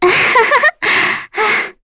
Lavril-Vox_Happy3.wav